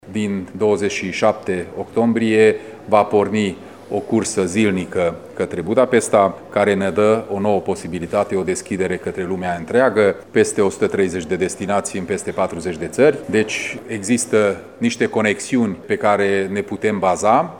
Președintele CJ Mureș, Peter Ferenc: